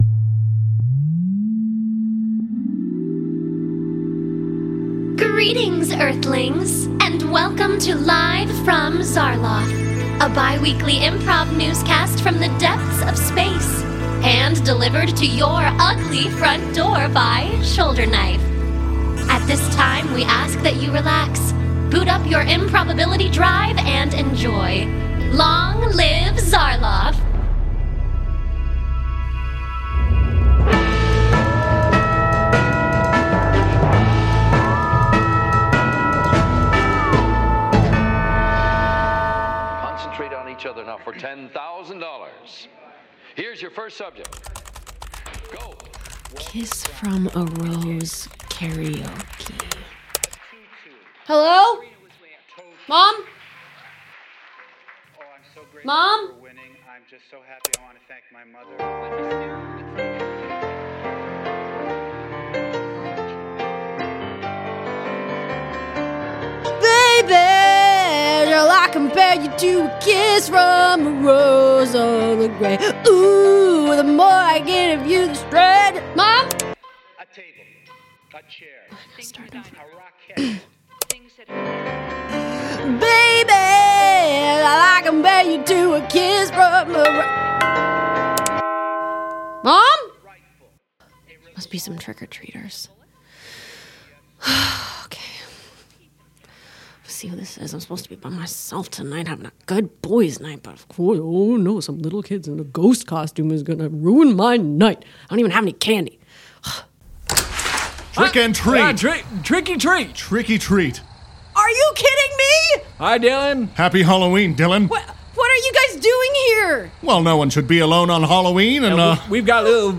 The LFZ Halloween Special! On the spookiest night of the year, Dylan Johnson of Earth must face his greatest fears.